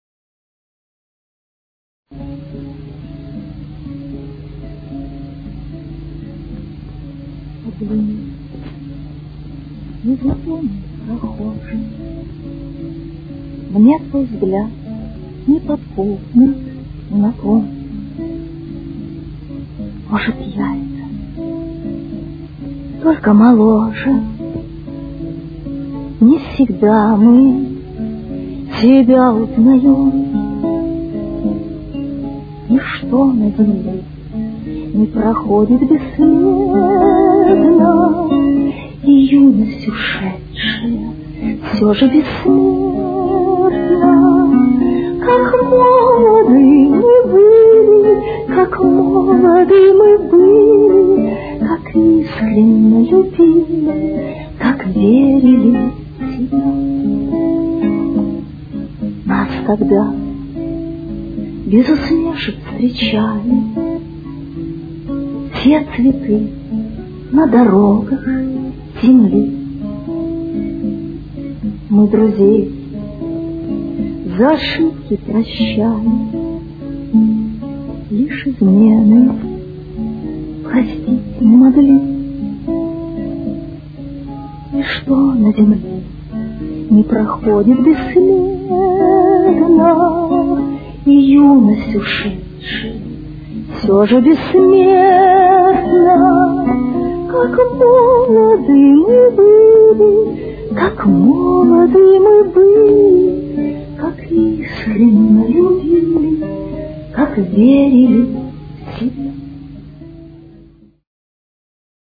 Темп: 77.